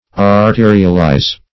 Arterialize \Ar*te"ri*al*ize\, v. t. [imp. & p. p.